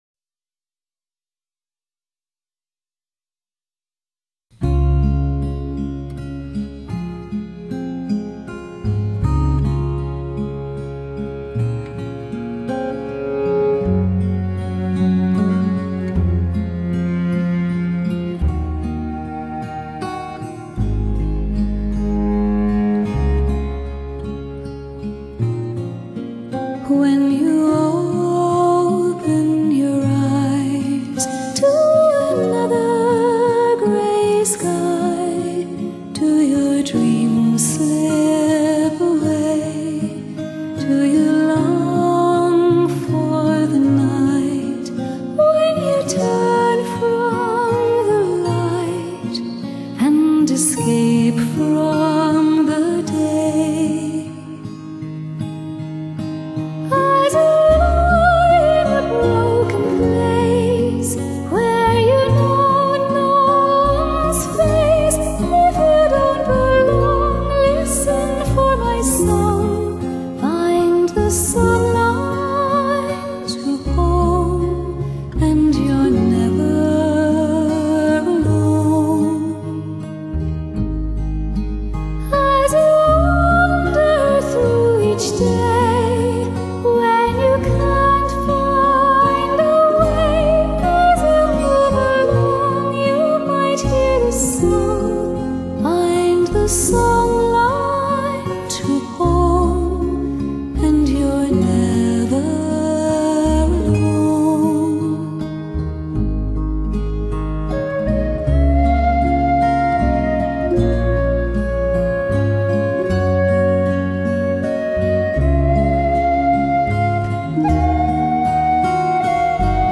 擁有甜美純淨的嗓音
其天使般澄澈純美的聲線幾乎無人可及